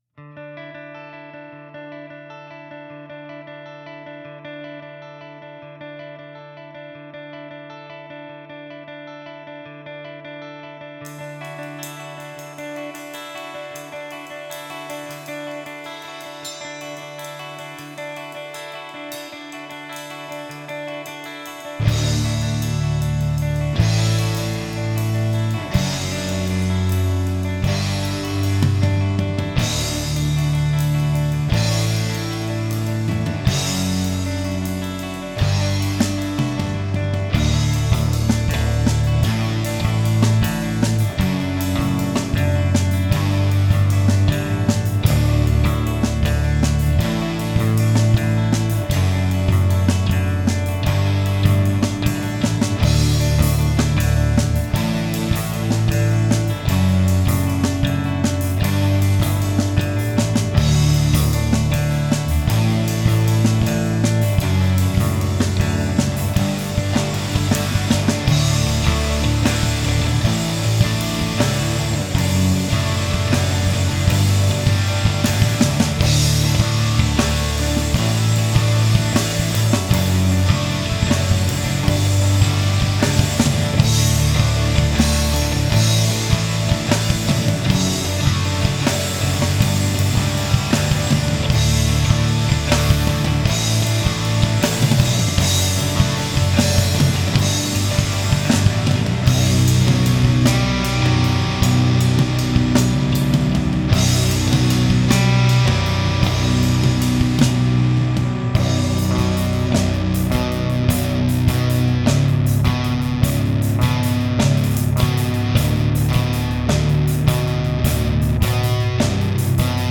I feel the best way for me to convey a dreamlike state is to abandon vocals and take the instrumental route.
Also, I decided against repeating sections (save for the guitars in 7/4 at the beginning of the tune) in order to give the tune a sense of forward motion. At the end of the day, a LOT of guitar tracks were recorded and I’m really happy with the results.